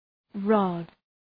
rod.mp3